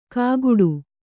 ♪ kāguḍu